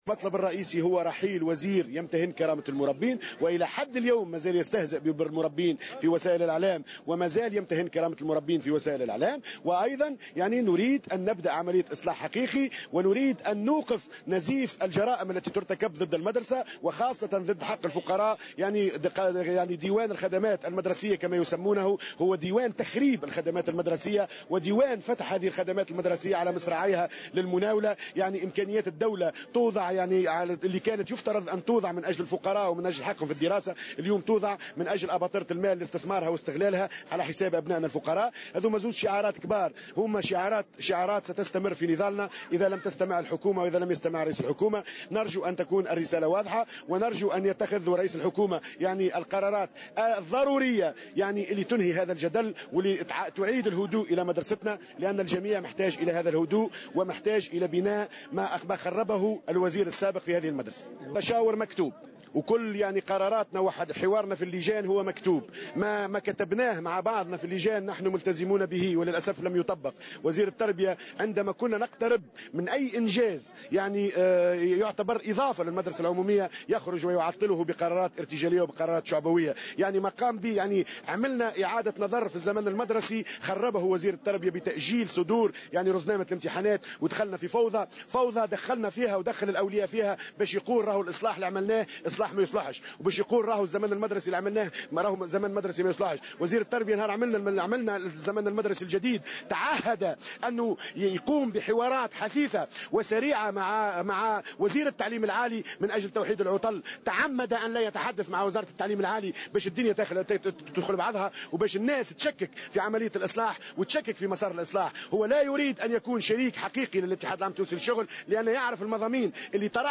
على هامش الوقفة الاحتجاجية التي نظمها الأساتذة والمعلمون اليوم